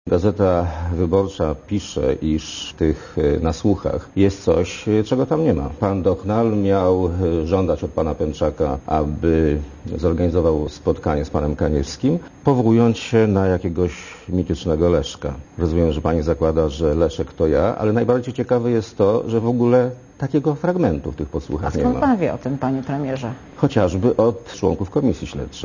* Mówi Leszek Miller*